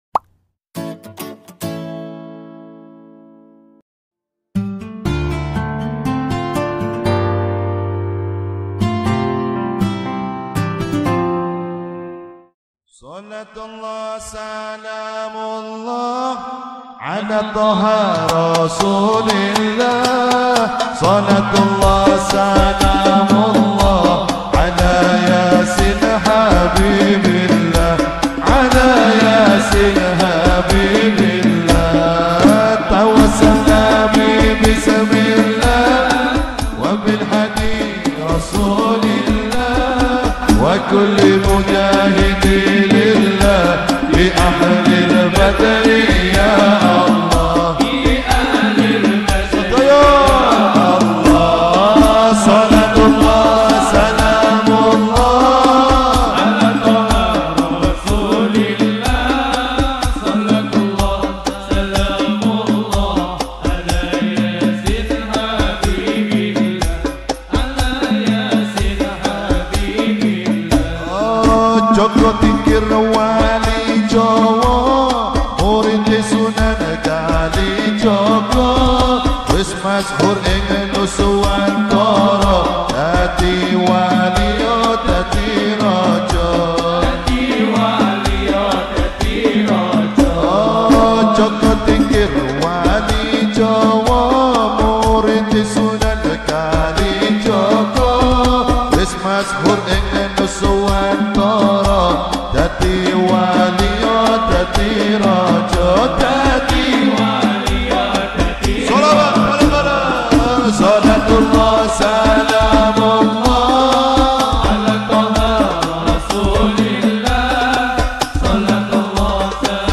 New Sholawat